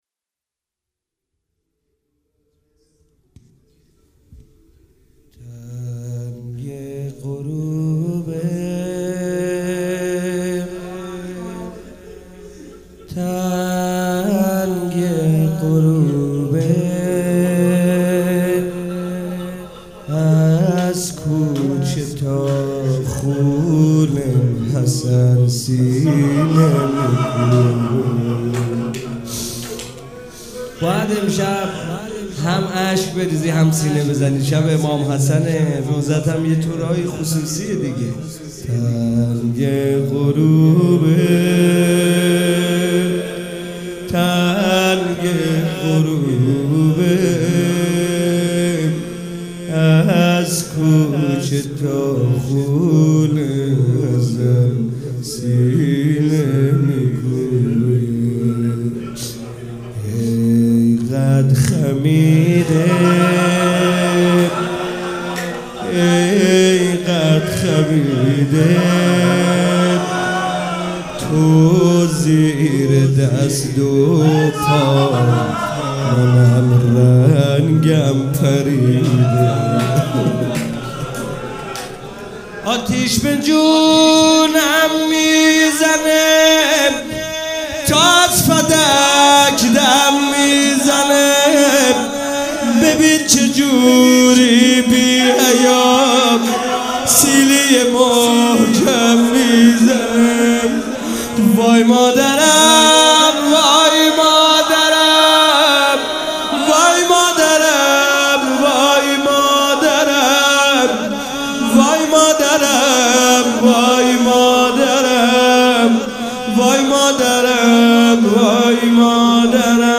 شب 7 فاطمیه 95 - زمینه - تنگ غروبه از کوچه تا مسجد